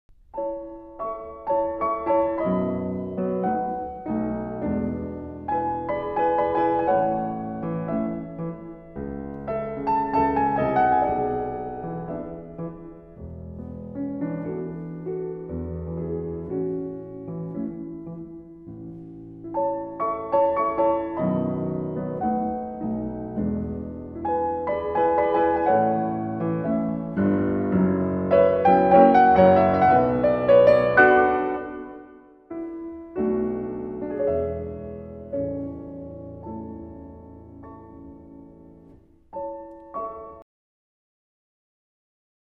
concert tango in A Major